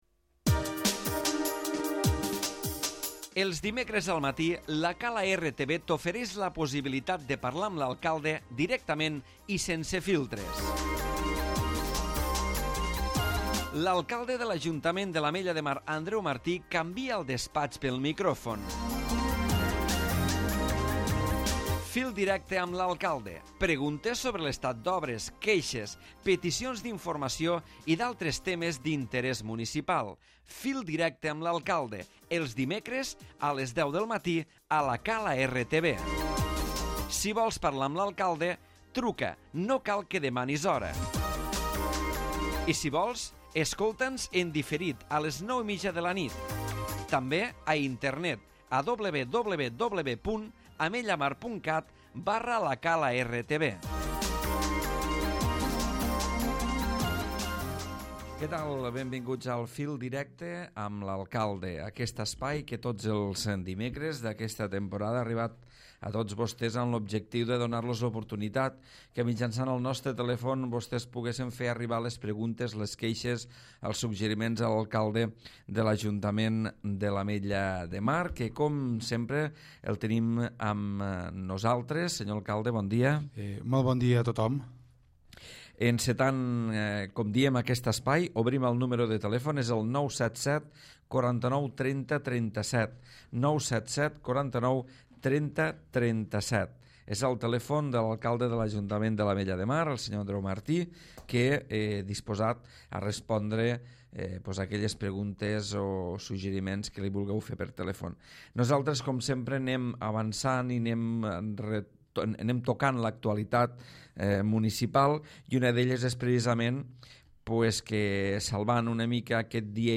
Andreu Martí, alcalde de l'Ajuntament de l'Ametlla de Mar, ha participat al darrer programa Fil Directe de la temporada, repassant l'actualitat i atenent les trucades dels ciutadans.